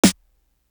Crushed Linen Snare.wav